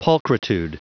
Prononciation du mot pulchritude en anglais (fichier audio)
Prononciation du mot : pulchritude